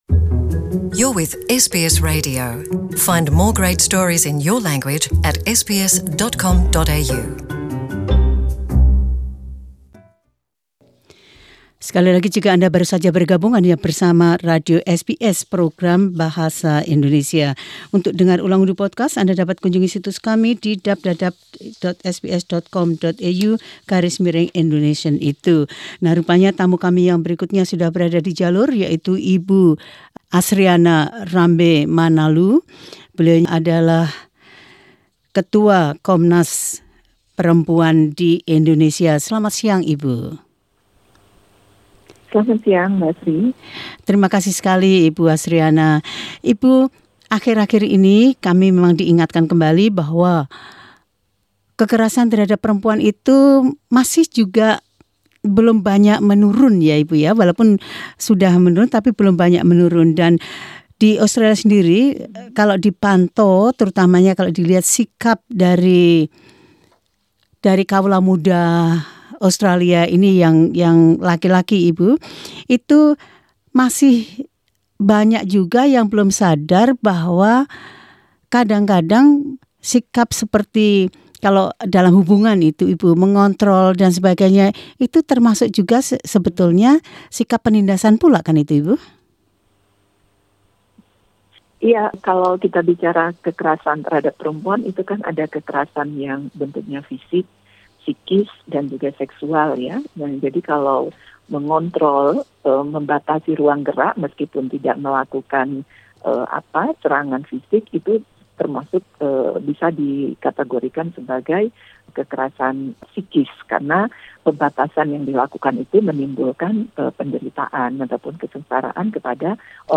Azriana Rambe Manalu, chairwomen of the National Commission on Violence Against Women talks about how discriminatory views seem to persist, even among younger men.